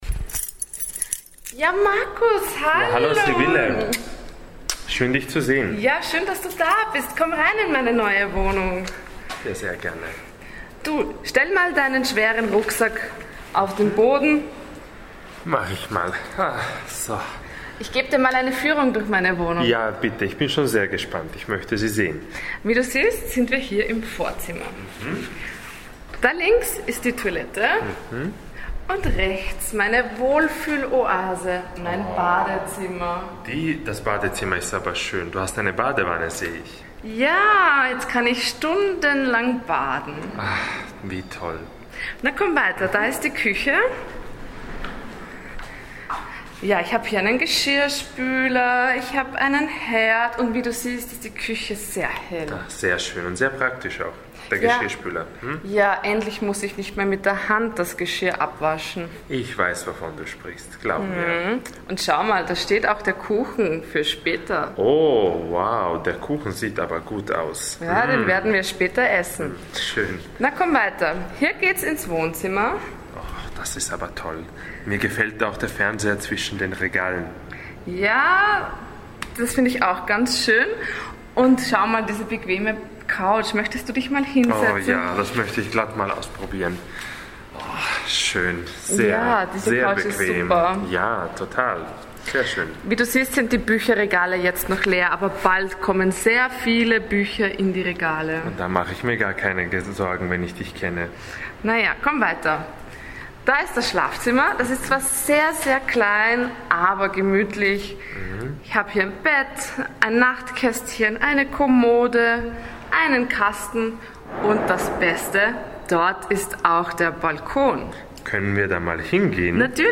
Die Sprecher und Sprecherinnen sprechen dann schneller, sie verwenden mehr Umgangssprache, sie verschlucken einige Silben und beenden ihre Sätze gar nicht richtig.